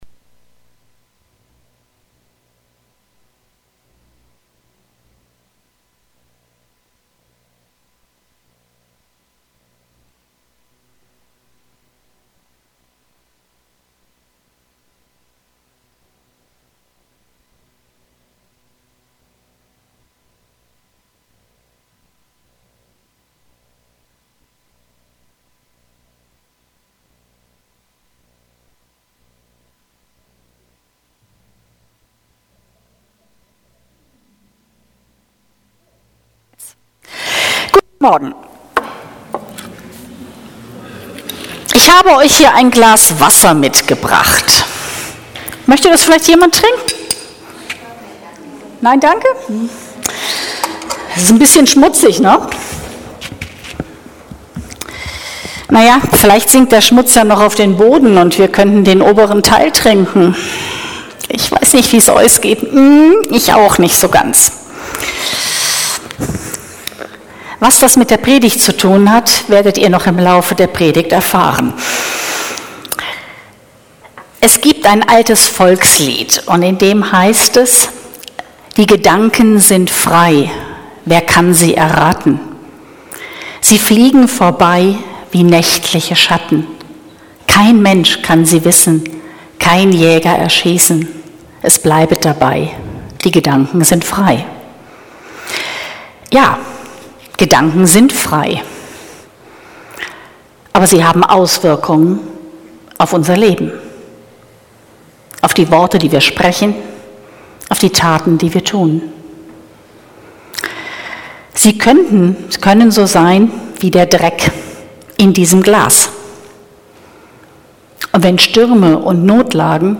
15.05.2022 Thema: Unreine Gedanken Prediger